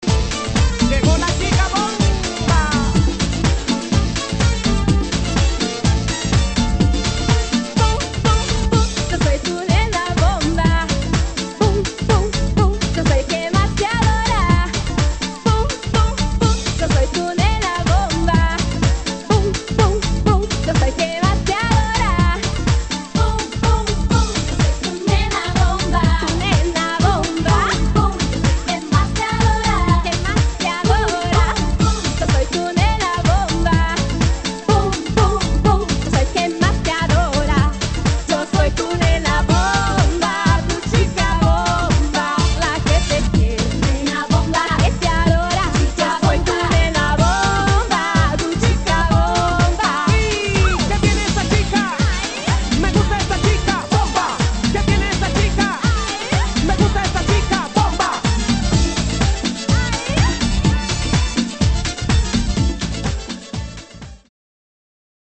[ HOUSE / LATIN ]
ラテン〜キューバン乗りのホット・ダンス・チューン！